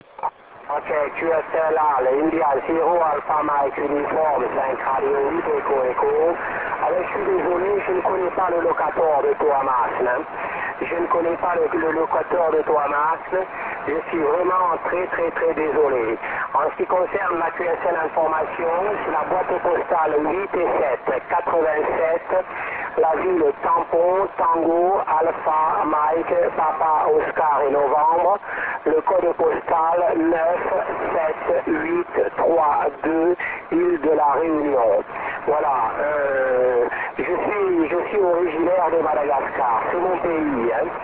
5R8EErealaudio at 1505-1545Z (VERY STRONG!!! all italy & CT3, F).